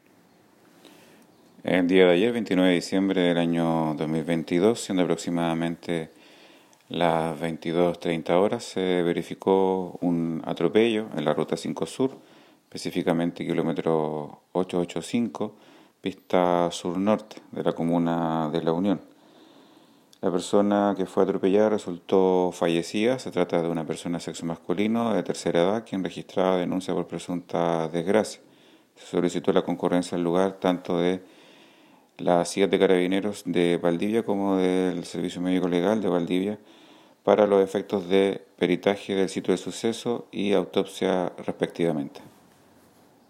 El fiscal Raúl Suárez  indicó que se investiga  la muerte de un adulto mayor, como consecuencia de un atropello ocurrido anoche en el kilómetro 885 de la Ruta 5 Sur, en la comuna de La Unión.